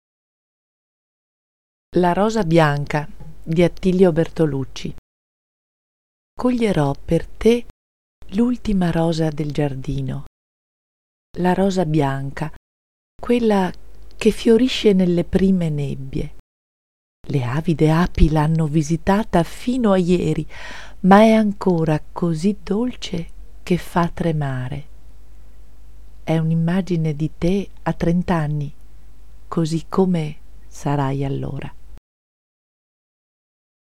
Inserito in Poesie recitate da docenti